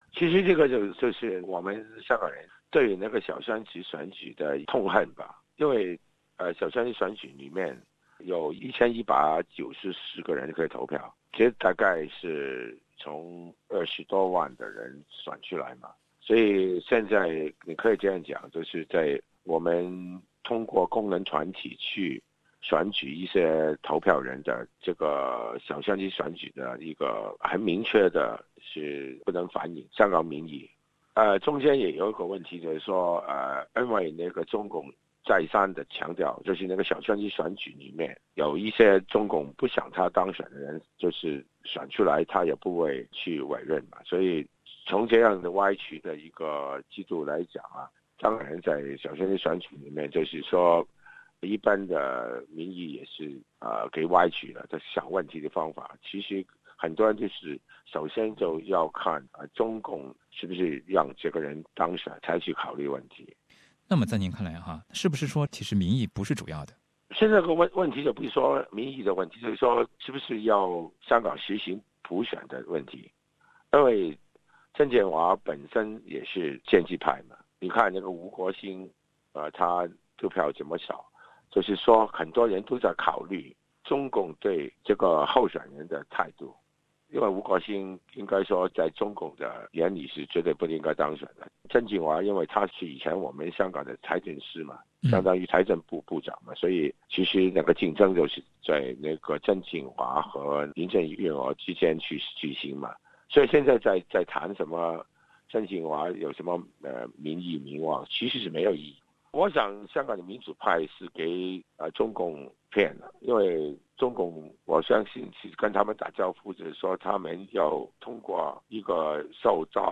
他们就民调支持率和新特首的执政等发表了自己的看法。梁国雄首先表达了他对小圈子选举的看法。